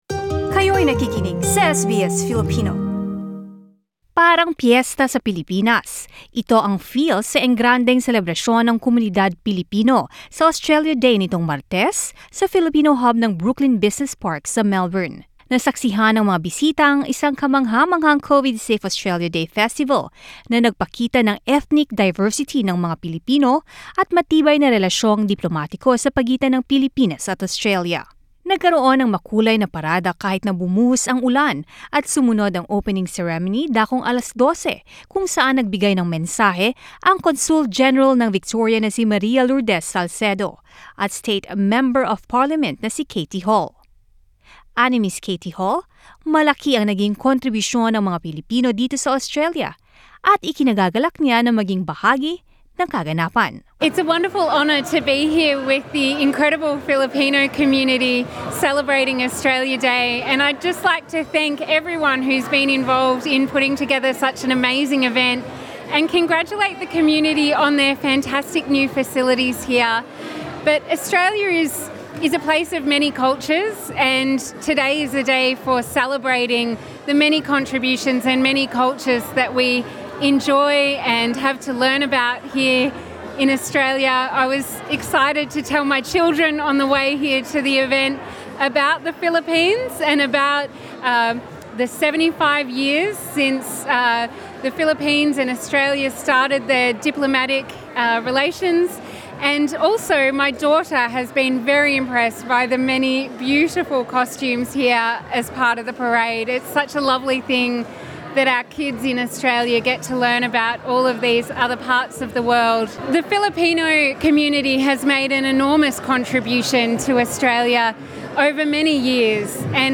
Filipinos in Victoria have come together for the Filipino-Australian community Australia Day festival Source: SBS Filipino